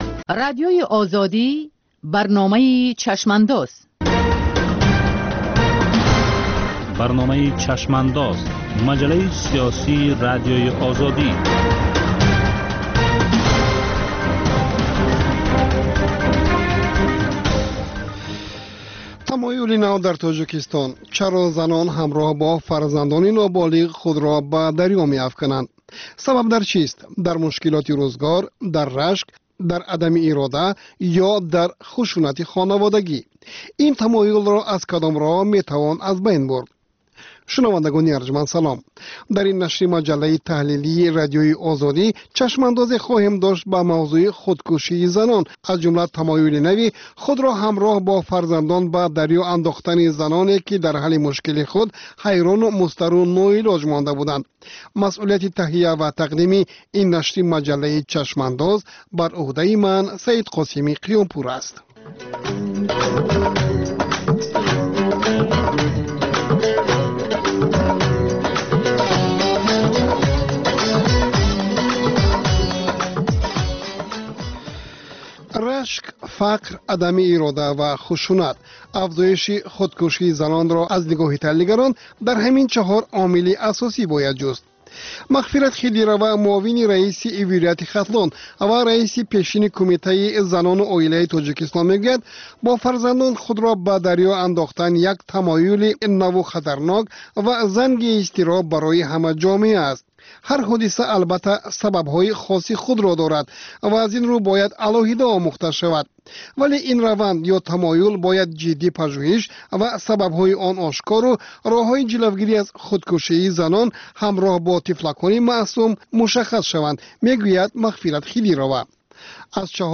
Нигоҳе ба таҳаввулоти сиёсии Тоҷикистон, минтақа ва ҷаҳон дар ҳафтае, ки гузашт. Гуфтугӯ бо сиёсатмадорон ва коршиносон.